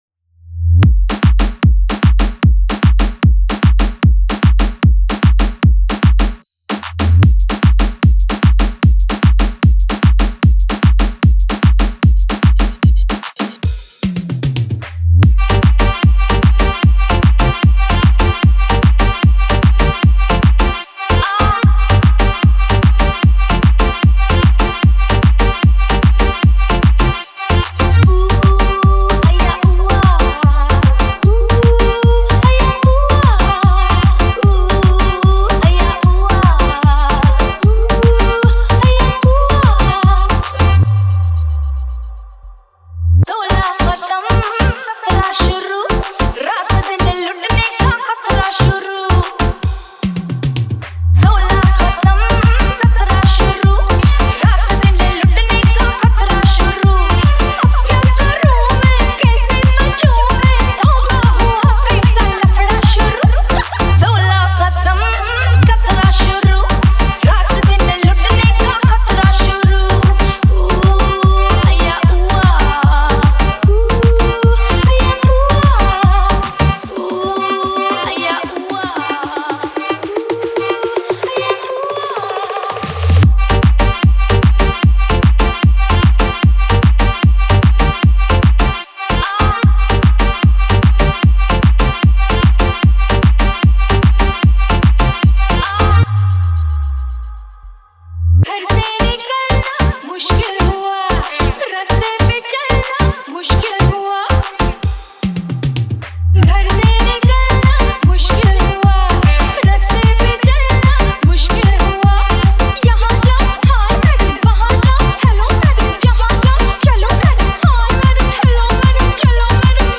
2025 Bollywood Single Remixes Song Name